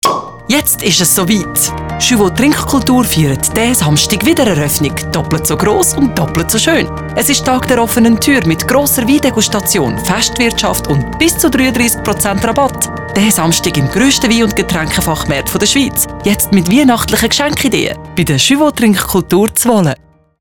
Radio Spot: Tag der offenen Tür 22.11.2014
schuewo-wiedereroeffnung-20sek.mp3